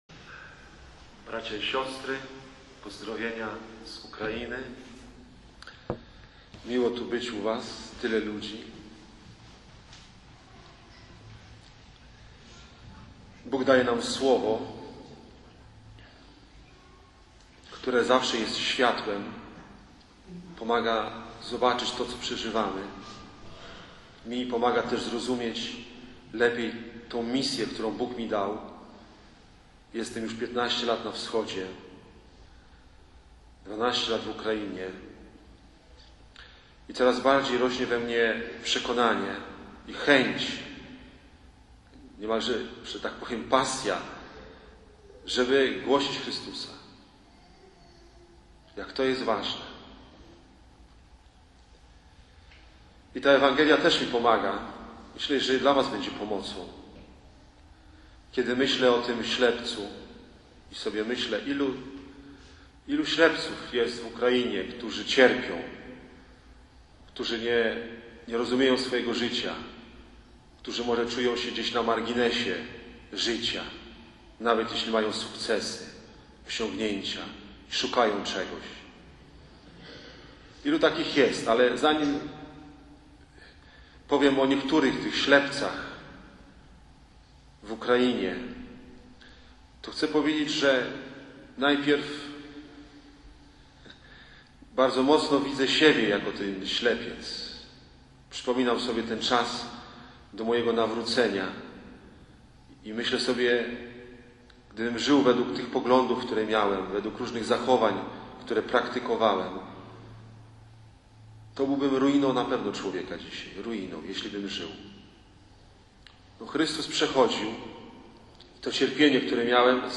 W niedzielę 28.10.12 r. obchodziliśmy Niedzielę Misyjną.
Posłuchaj Słowa Bożego (18:01 min): Plik mp3 do pobrania (8,5 MB) Whatsapp